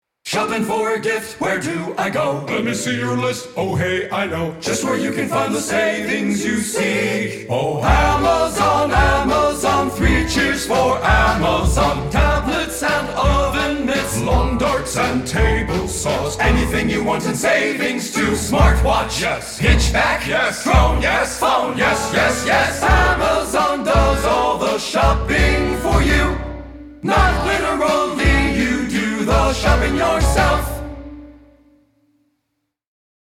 Choral Men
A cappella